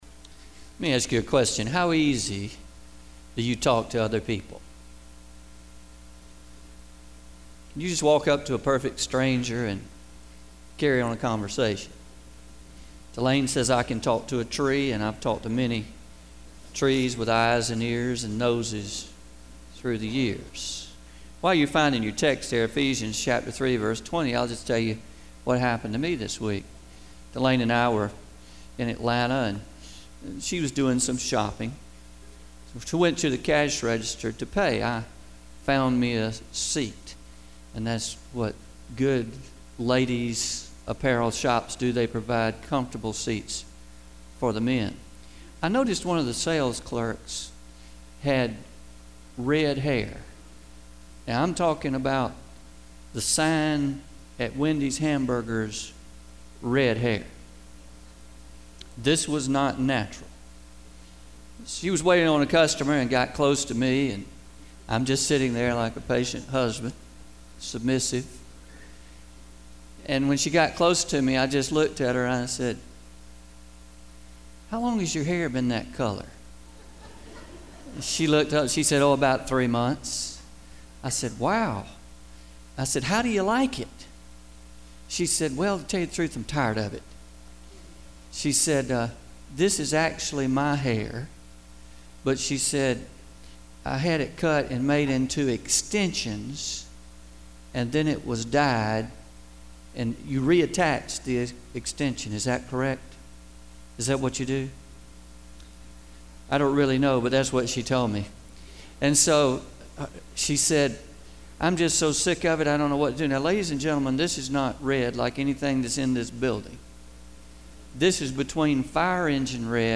Your Request and God’s Best – (Pre-Recorded)